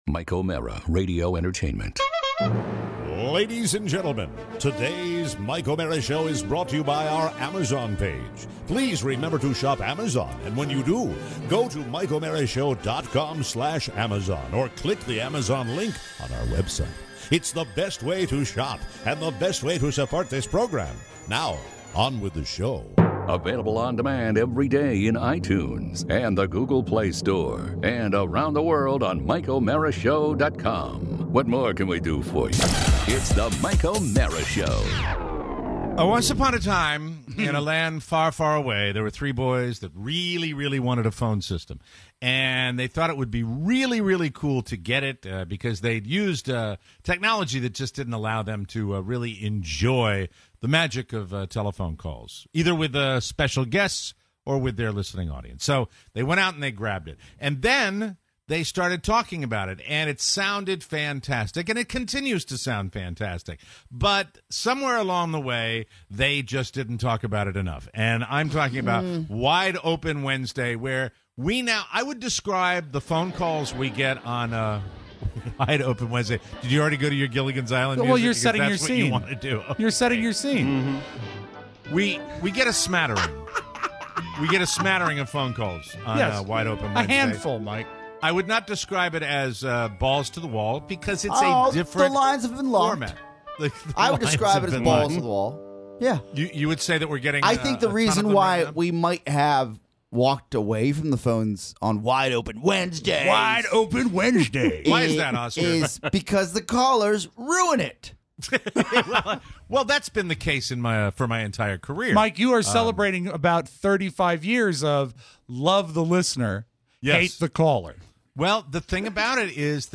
Plus, a new floor…actual pug audio… pin ups… Ambien… and your calls.